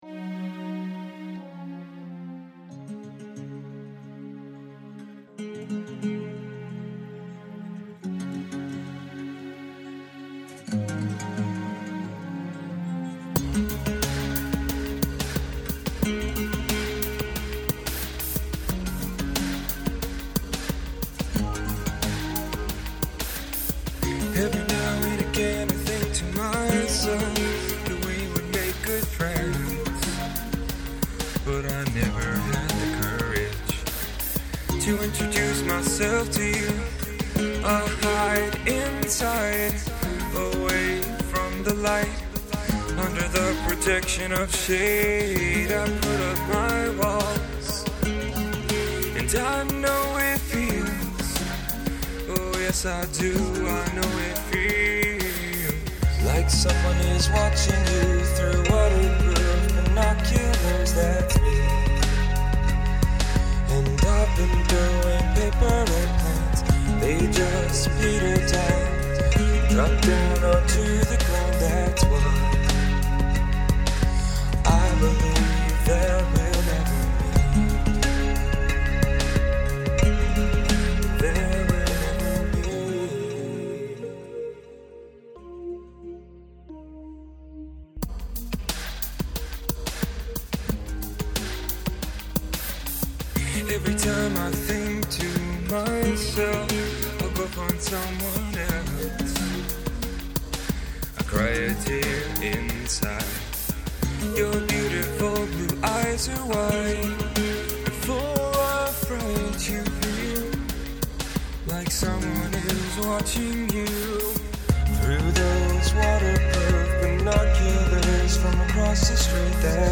December 30, 2009 | Drop D Tuning
Paper-Airplanes-Electronic.mp3